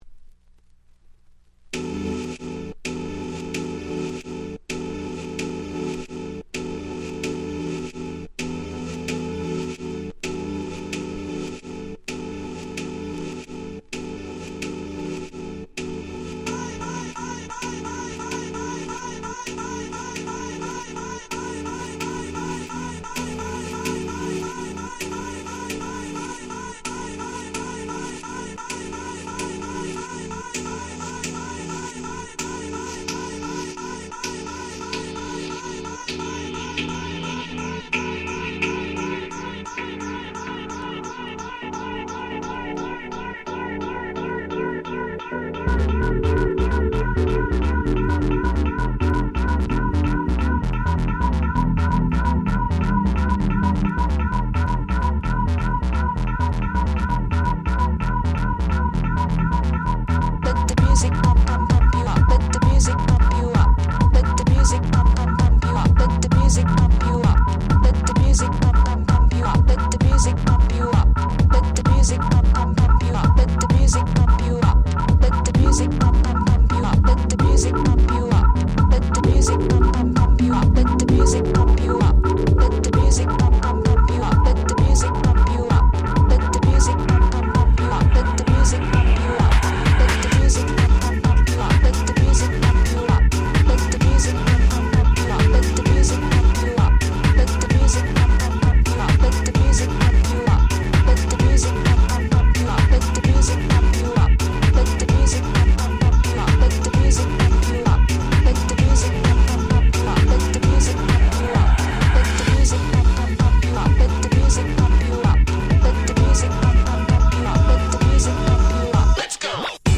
02' Very Nice Dance Classics Remixes !!